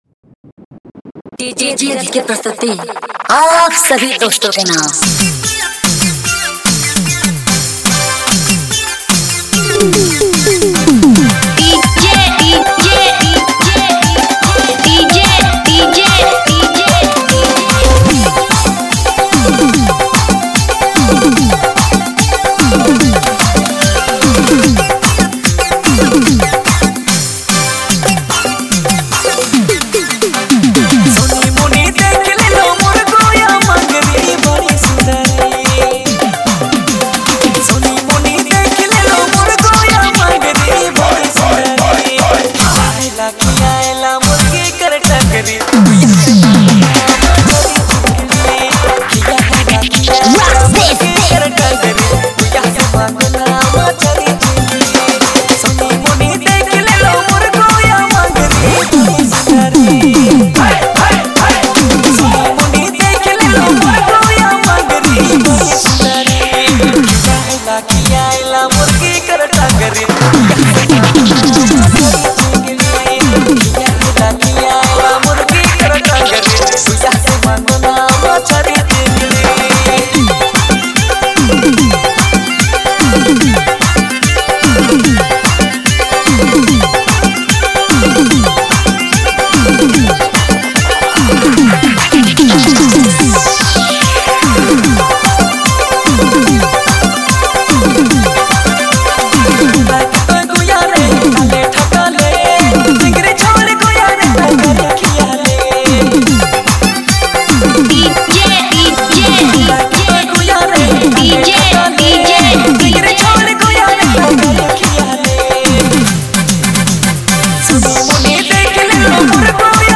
traditional folk song